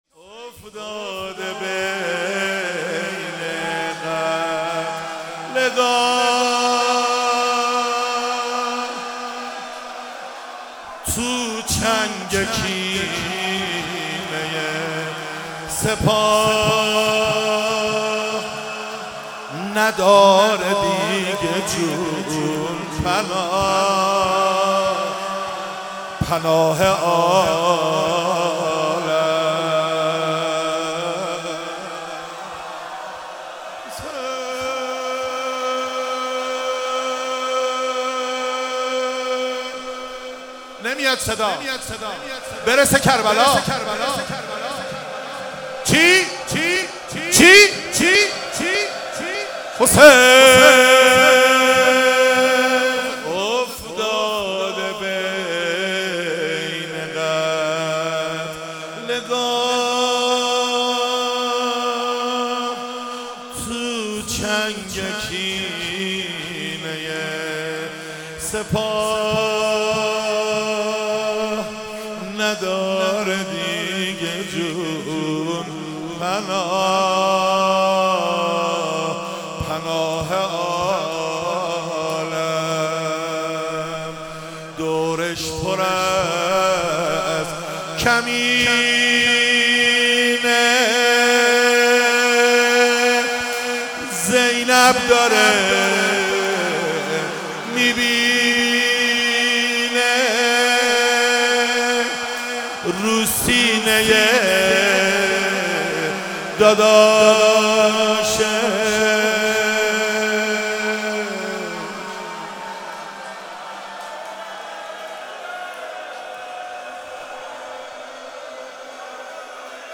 ولادت حضرت عباس (ع)